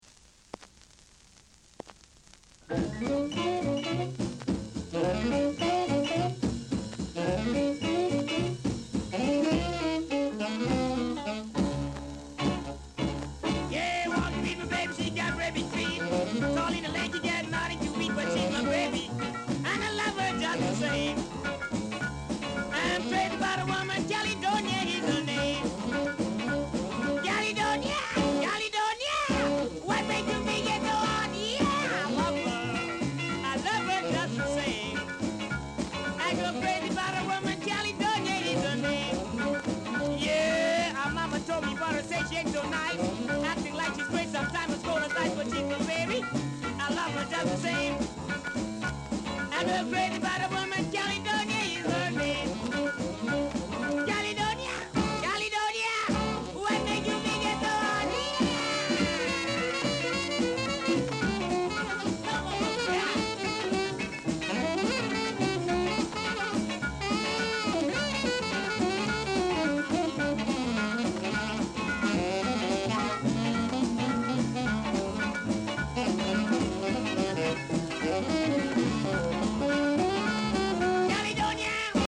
Blues Male Vocal
Rare! bad Ja blues vocal w-sider!
カリプソニアンが唄うハードブルーズ！